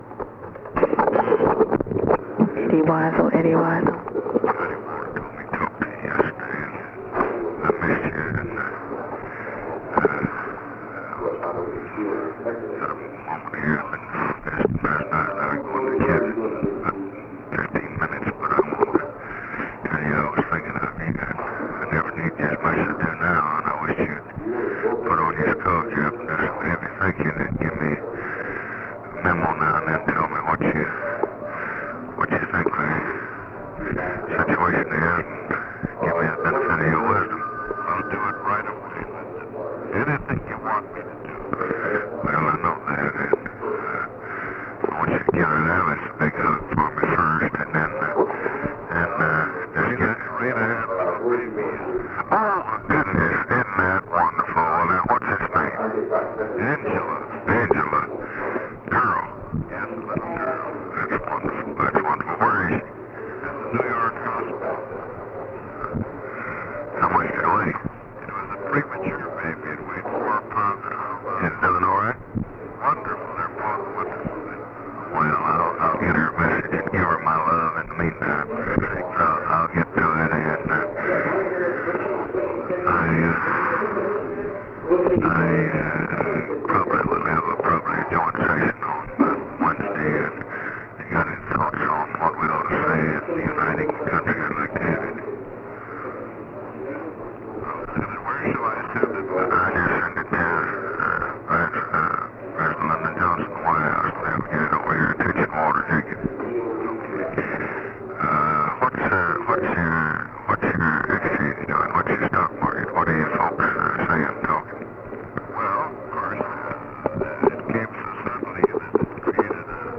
Conversation with EDWIN WEISL, SR., November 23, 1963
Secret White House Tapes